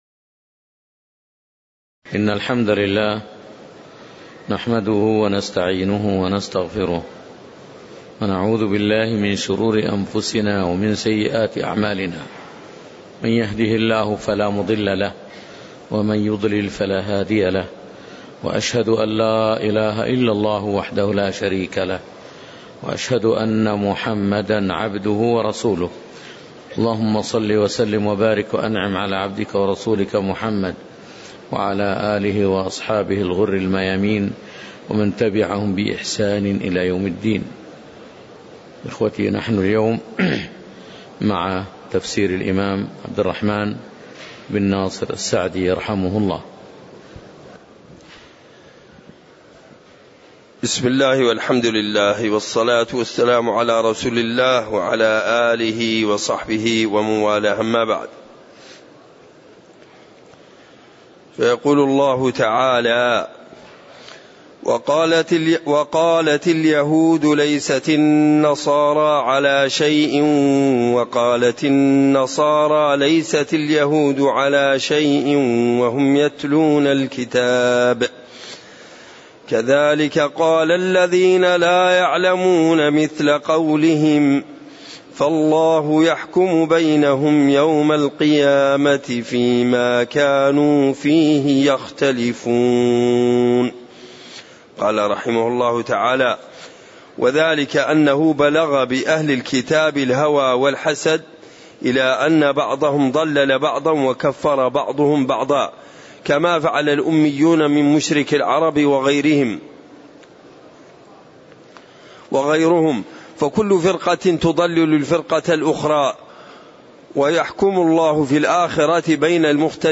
تاريخ النشر ١٥ ربيع الثاني ١٤٣٨ هـ المكان: المسجد النبوي الشيخ